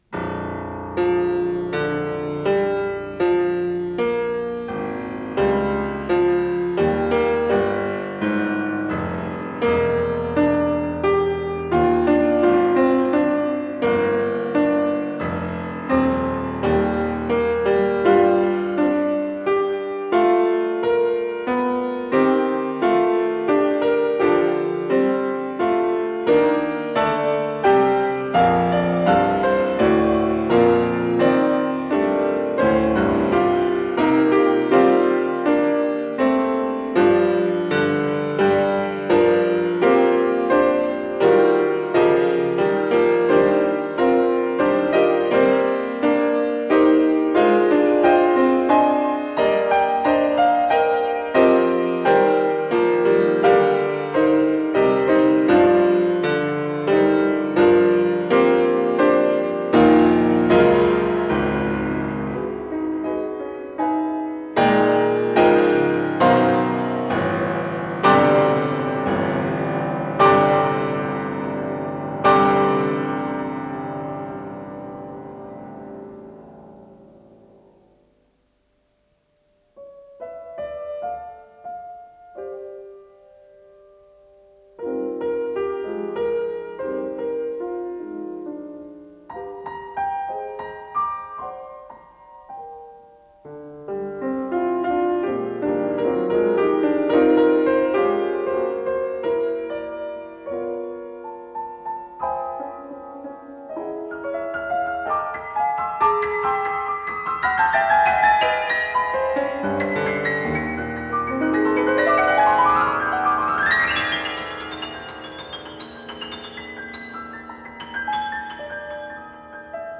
фортепиано
Цикл пьес для фортепиано